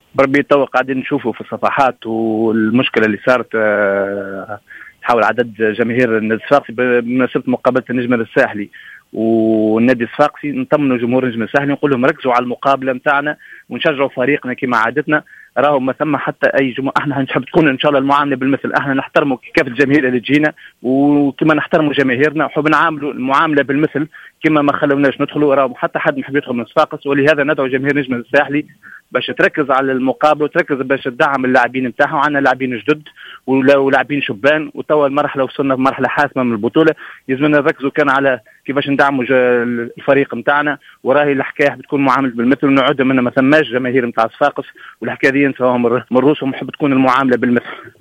في مداخلة على جوهرة أف أم